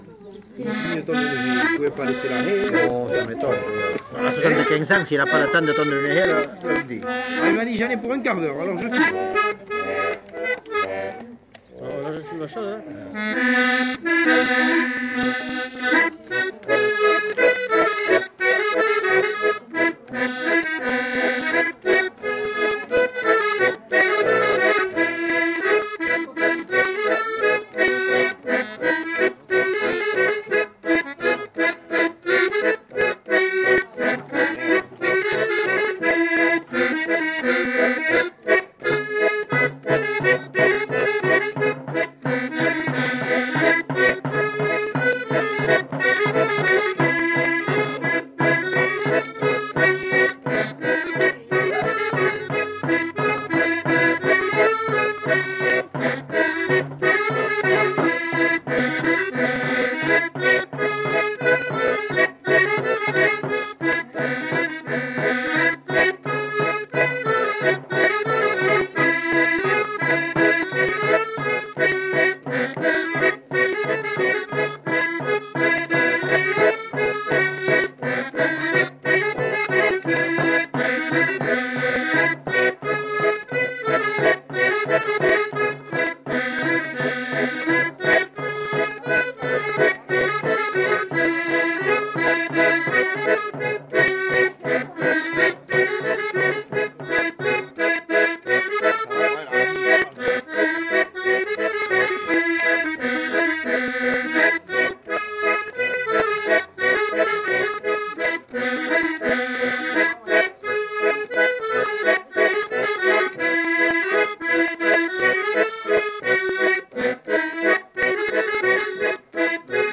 Aire culturelle : Grandes-Landes
Lieu : Luxey
Genre : morceau instrumental
Instrument de musique : accordéon diatonique ; percussions
Danse : scottish